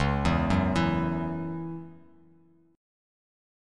C#mbb5 chord